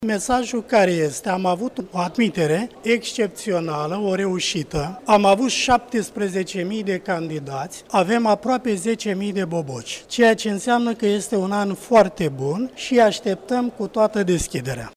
La Universitatea Alexandru Ioan Cuza din Iaşi s-a înregistrat un număr record de participanţi la admiterea din sesiunea de vară, a declarat,  astăzi, rectorul Tudorel Toader, la deschiderea noului an academic.
Rectorul Universităţii „Alexandru Ioan Cuza” din Iaşi, Tudorel Toader: